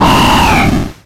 Cri de Barbicha dans Pokémon X et Y.